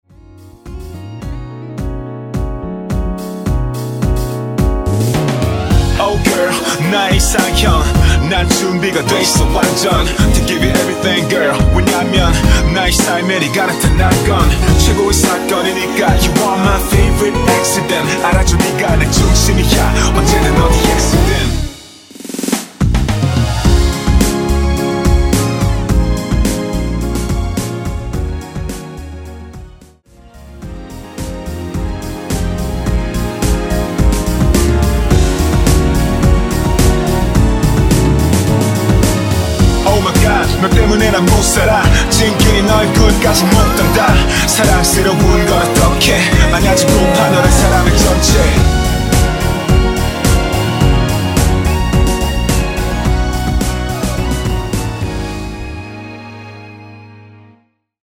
반키(-1) 내린 랩추가된 MR 입니다.(파란색 가사 부분과 미리듣기 참조 하세요)
Db
앞부분30초, 뒷부분30초씩 편집해서 올려 드리고 있습니다.